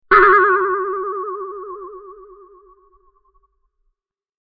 Boing Sound Effect Download
Add this funny cartoon boing sound to your videos, animations, and games. Use this comedic audio clip to create playful, fun moments in your projects.
Genres: Sound Effects
Boing-sound-effect-download.mp3